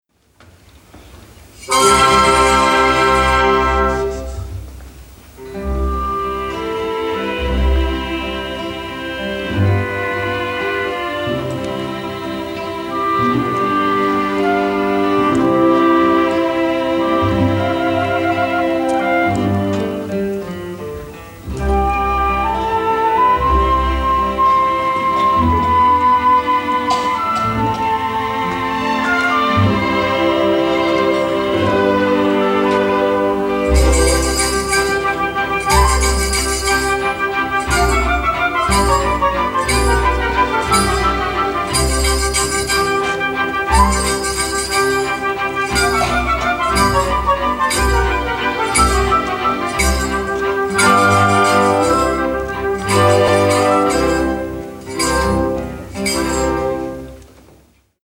Orchestra e Coro del Teatro dell'Opera di Marsiglia
Registrazione dal vivo del 21 aprile 1998.
n.1-Cavatina.mp3